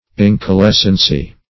Incalescency \In`ca*les"cen*cy\, n.